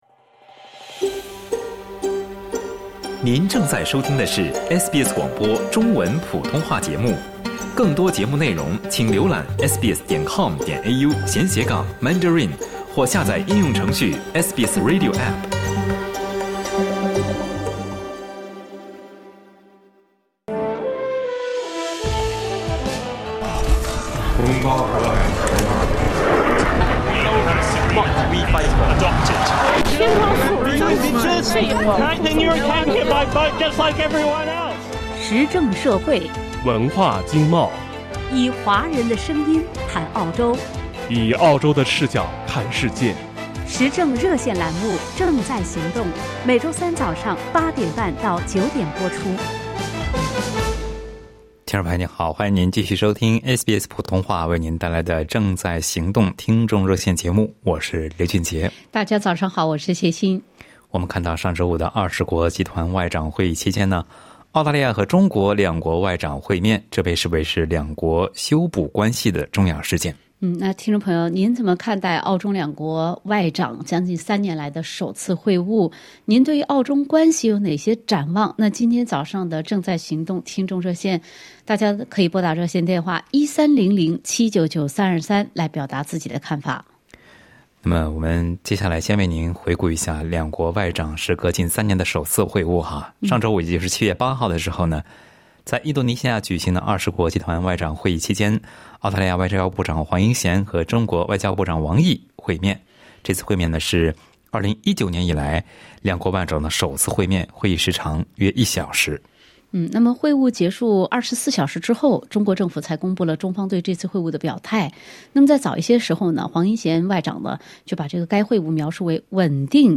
READ MORE 总理表示澳洲将继续就中国人权与贸易制裁问题提出关切 在本期《正在行动》听众热线节目中，听友们就澳中两国外长近三年来的首次会晤及澳中关系表达了自己的看法和展望。